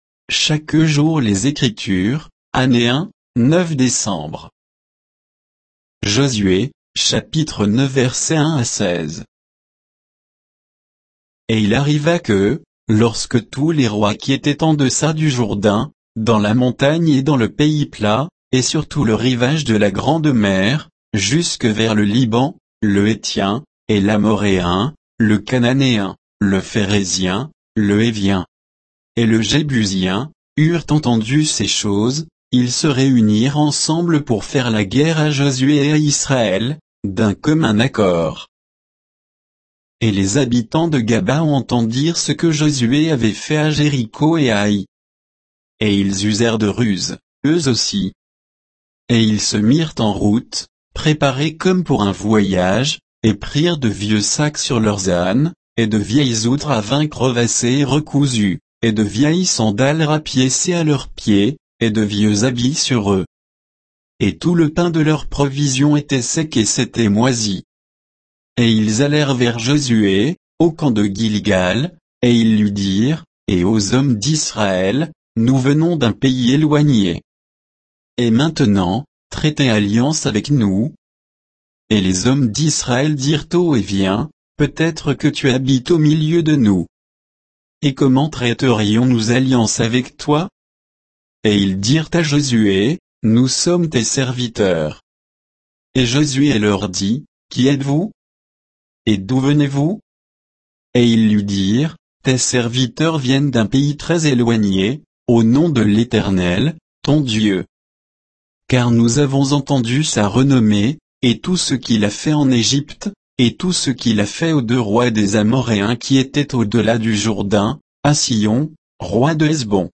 Méditation quoditienne de Chaque jour les Écritures sur Josué 9, 1 à 16